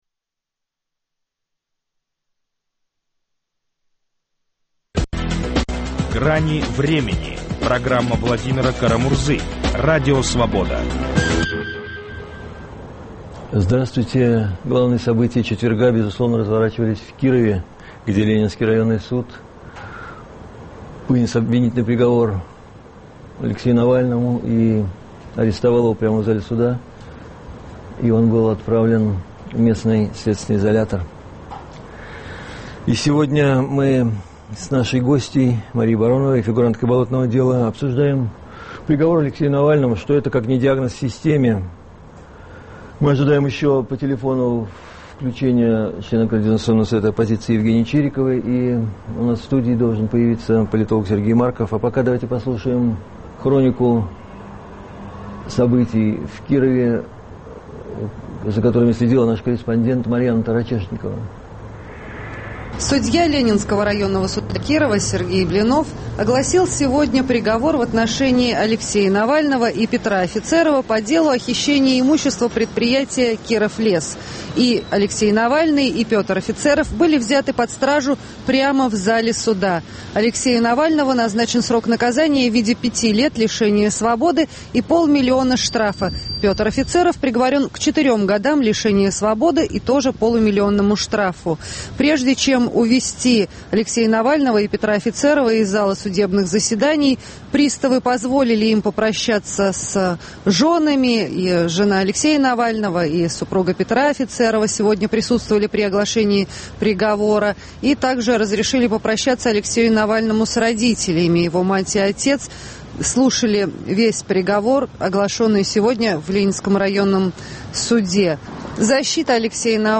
Приговор Навальному - диагноз системе. Беседуем с политологом Сергеем Марковым и фигуранткой "болотного дела" Марией Бароновой.